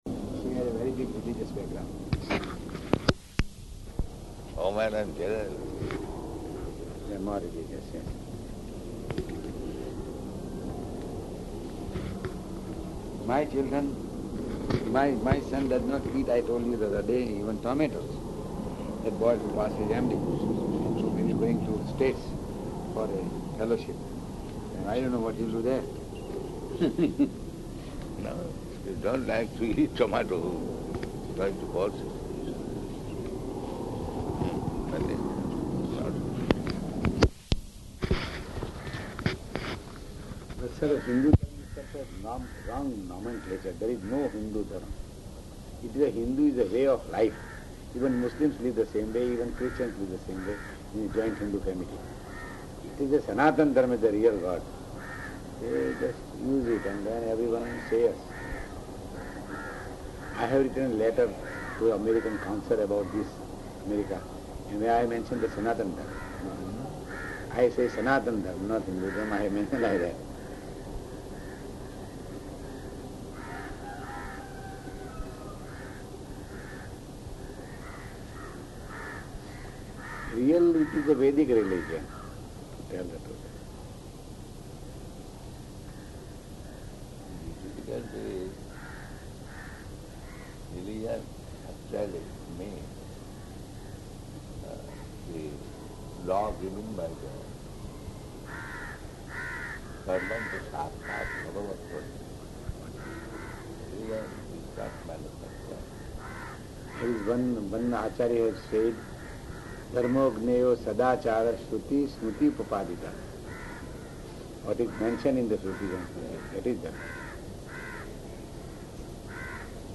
-- Type: Walk Dated: January 2nd 1977 Location: Bombay Audio file